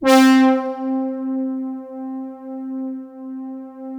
Index of /90_sSampleCDs/Best Service ProSamples vol.55 - Retro Sampler [AKAI] 1CD/Partition D/BRASS PAD